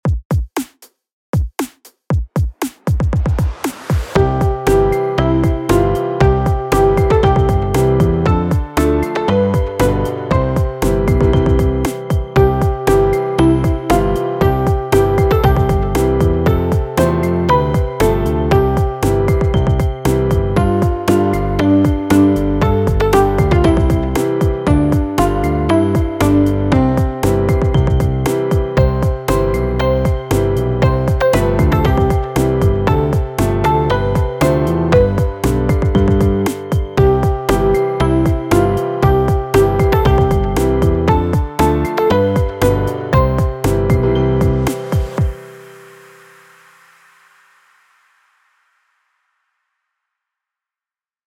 원가